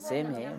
[semhe] noun snow/ice on the ground